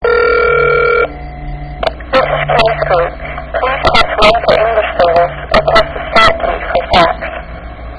City-Voice 數位音效
• 含數位化語音